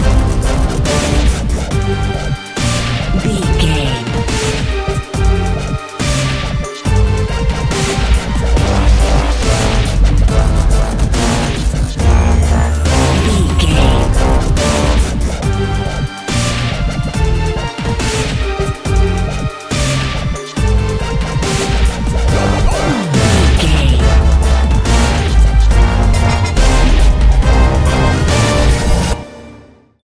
Aeolian/Minor
drum machine
synthesiser
orchestral
orchestral hybrid
dubstep
aggressive
energetic
intense
strings
drums
bass
synth effects
wobbles
epic